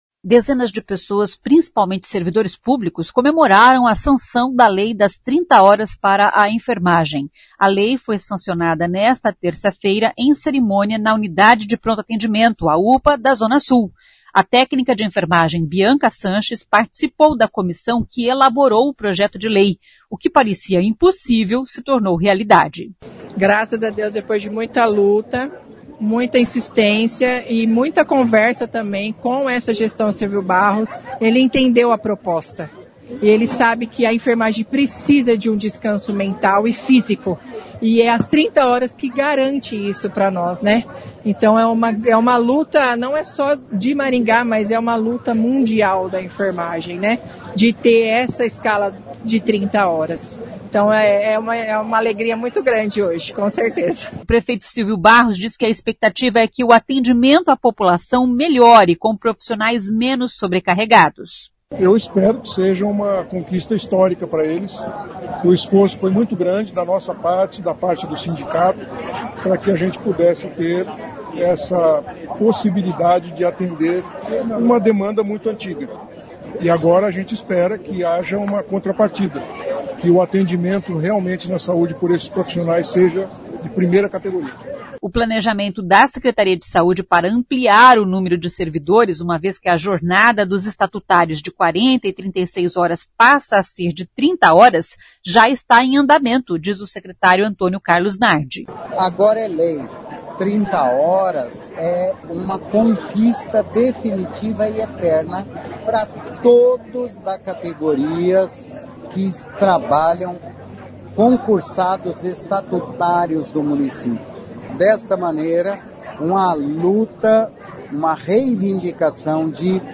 A cerimônia de assinatura da lei foi realizada na UPA Zona Sul.
O prefeito Silvio Barros diz que a expectativa é que o atendimento à população melhore com profissionais menos sobrecarregados.